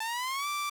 FX Sizzle Unfazed.wav